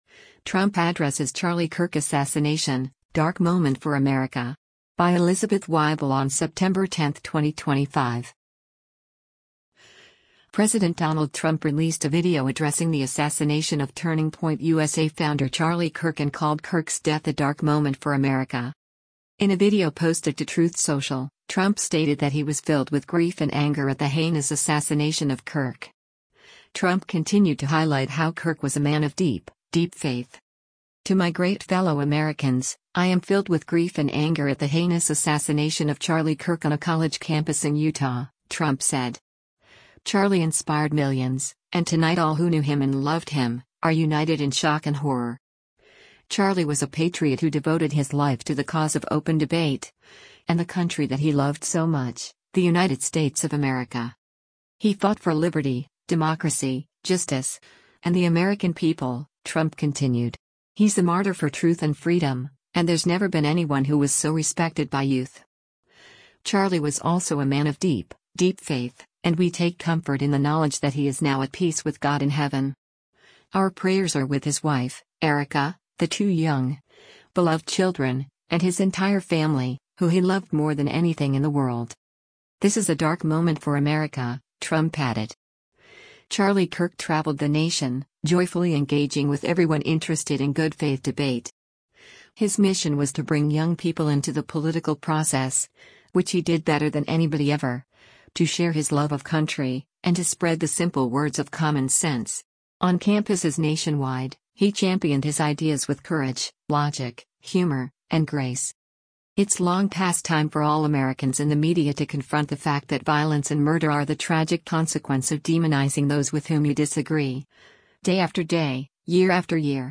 President Donald Trump released a video addressing the assassination of Turning Point USA founder Charlie Kirk and called Kirk’s death a “dark moment for America.”